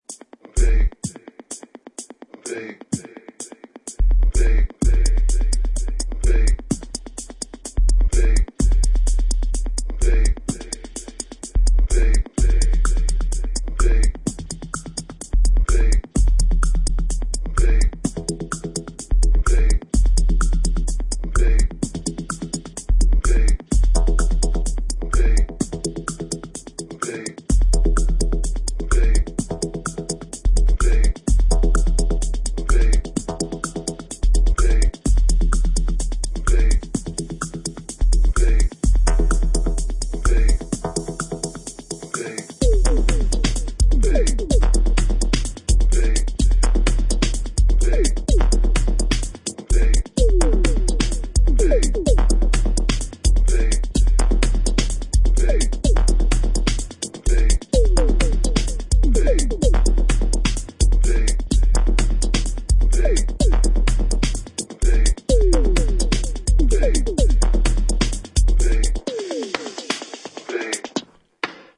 supplier of essential dance music
bass music
Electro Techno Bass